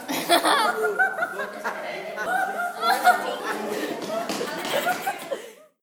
kids laughing